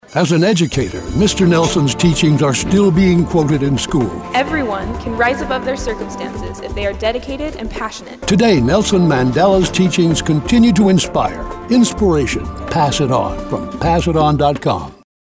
Listen to our new radio PSA featuring Nelson Mandela and the value of Inspiration